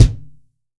TUBEKICKT5-S.WAV